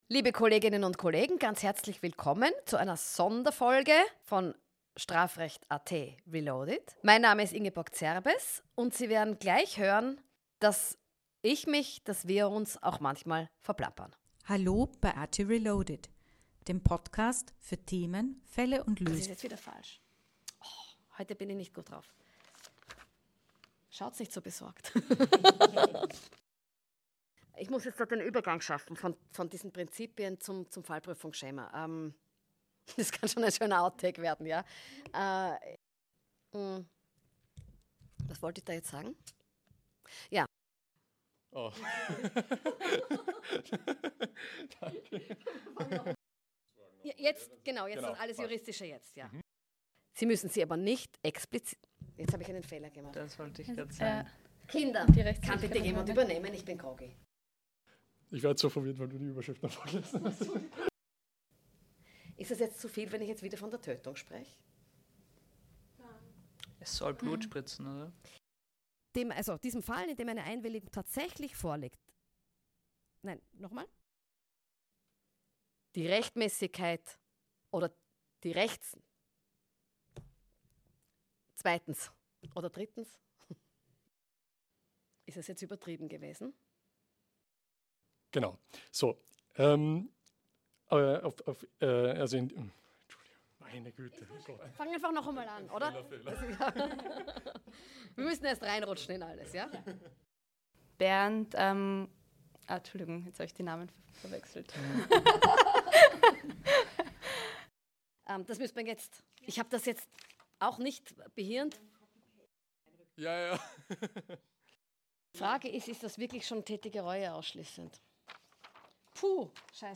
Versprecher, Denkpausen, falsche Übergänge und spontane Neustarts